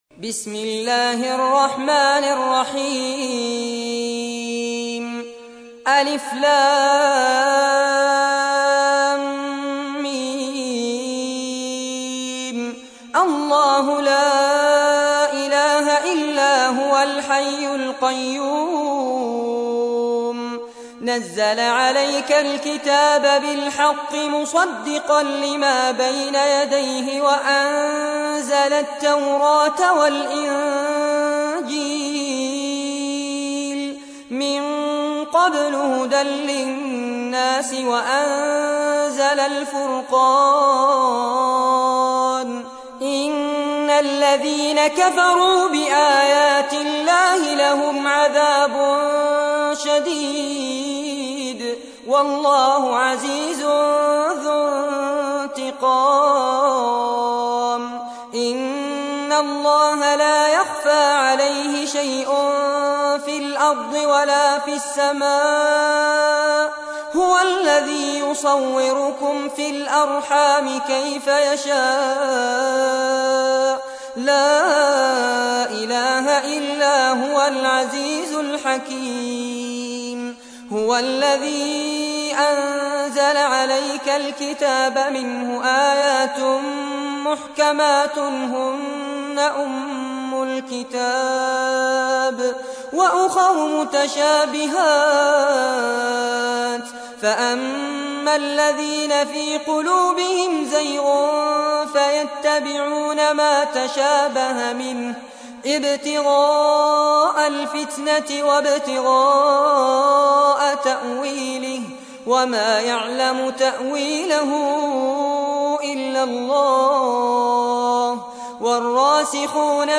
تحميل : 3. سورة آل عمران / القارئ فارس عباد / القرآن الكريم / موقع يا حسين